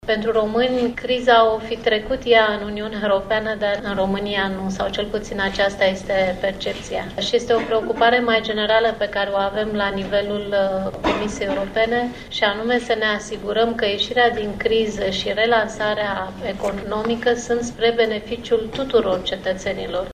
Şeful Reprezentanţei Comisiei Europene în România, Angela Filote: